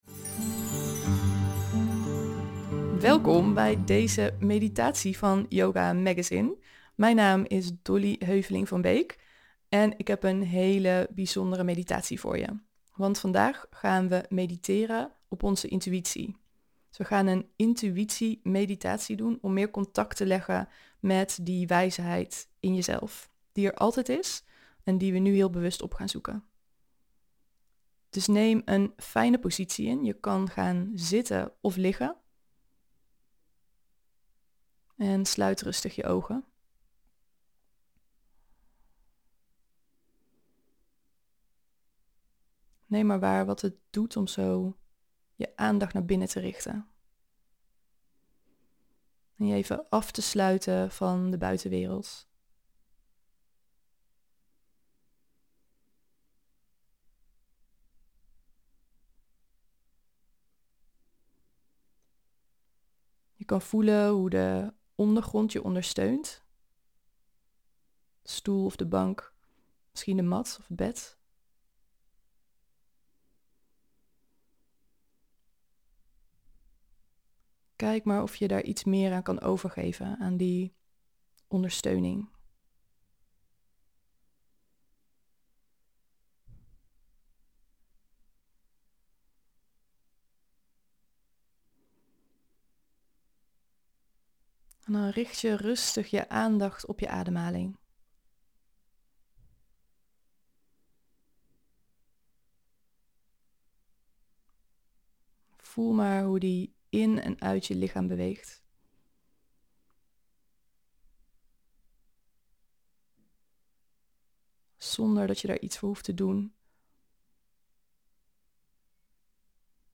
Leer luisteren naar je intuïtie met deze meditatie